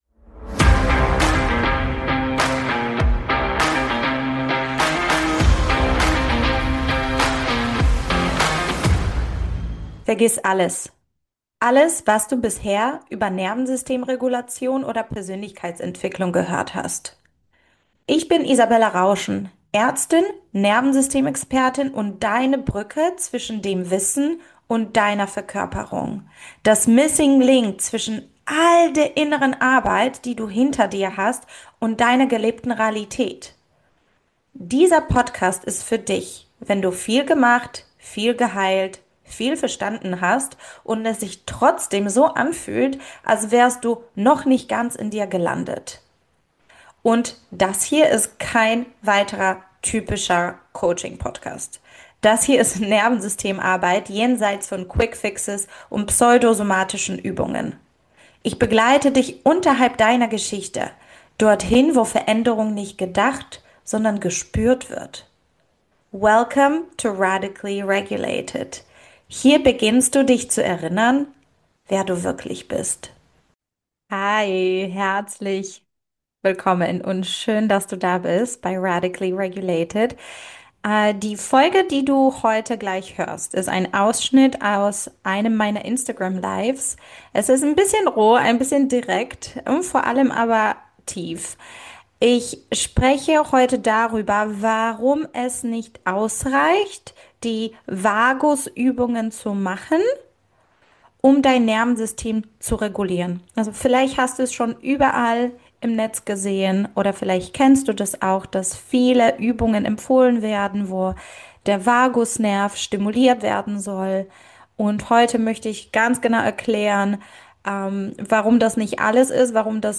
In dieser Folge nehme ich dich mit in ein rohes, tiefes Live aus meinem Instagram – und wir sprechen über eine der wohl größten Missverständnisse rund ums Nervensystem: Warum Vagus-Übungen zwar gut gemeint sind, aber oft nichts mit echter Regulation zu tun haben.